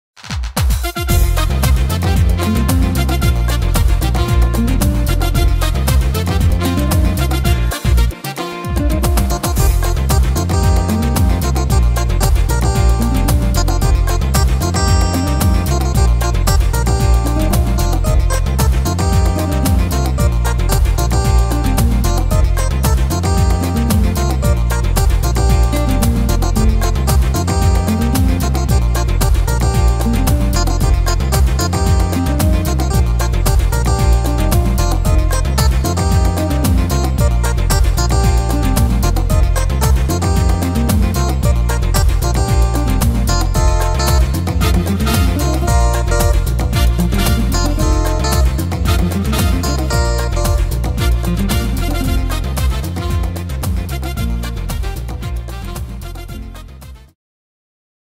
Tempo: 113 / Tonart: Bb-Dur